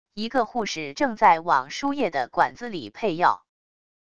一个护士正在往输液的管子里配药wav音频